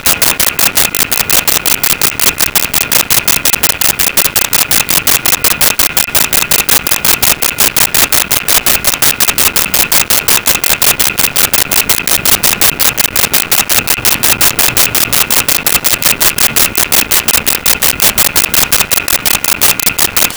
Phone Busy Signal
Phone Busy Signal.wav